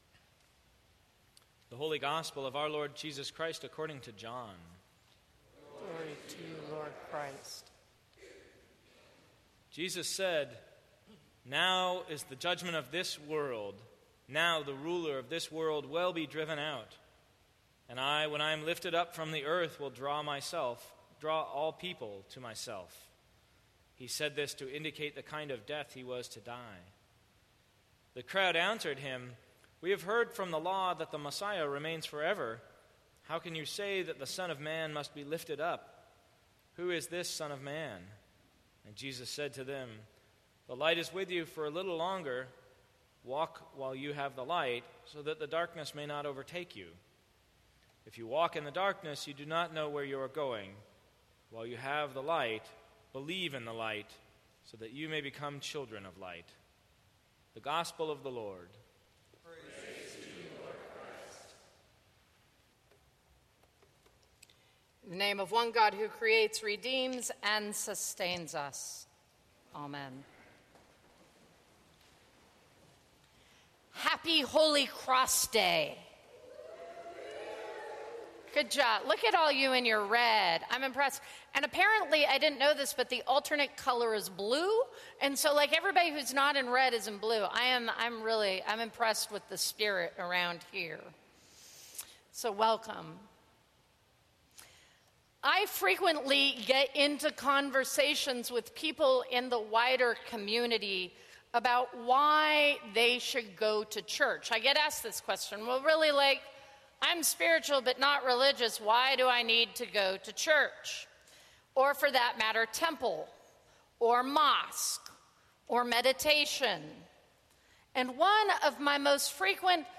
Sermons from St. Cross Episcopal Church Lift High the Cross Sep 24 2015 | 00:15:03 1x 00:00 / 00:15:03 Subscribe Share Apple Podcasts Spotify Overcast RSS Feed Share Link Embed